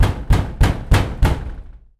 doorhammer.wav